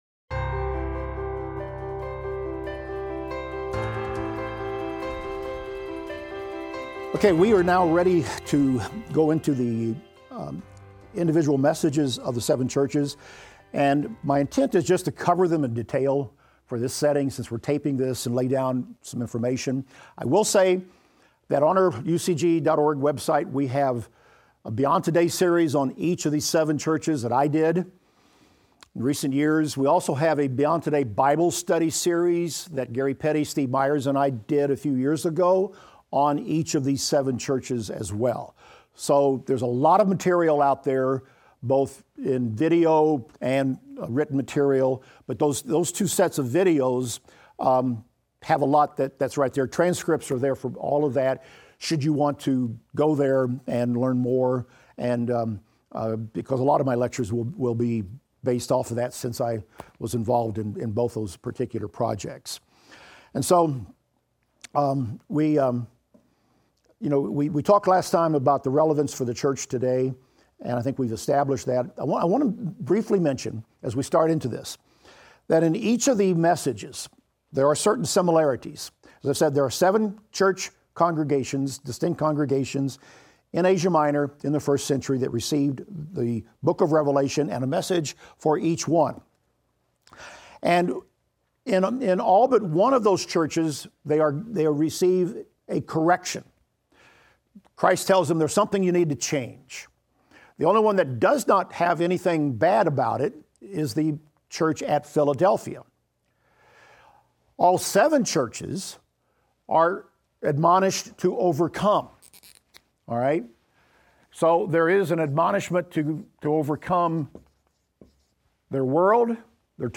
Revelation - Lecture 28 - audio.mp3